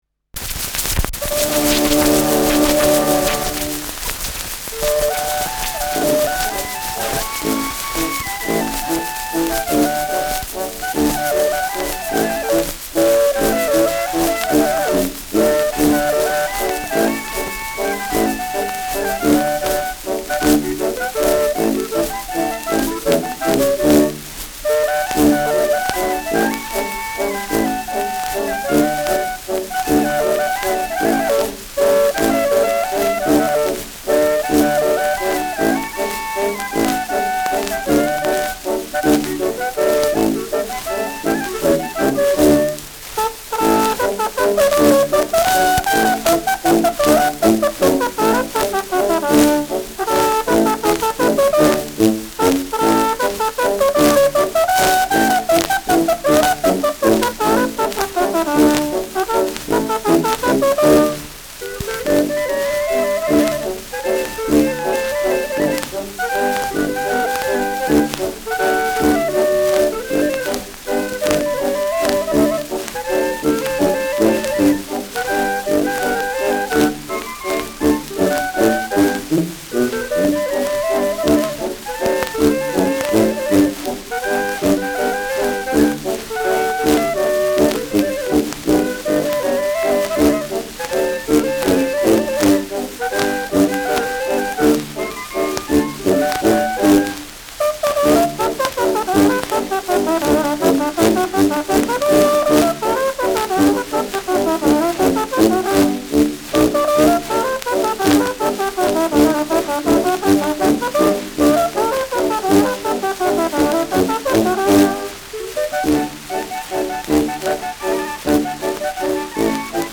Schellackplatte
Tonnadel springt zu Beginn : präsentes Rauschen : präsentes Knistern : abgespielt : leiert : gelegentliches Nadelgeräusch : gelegentliches Knacken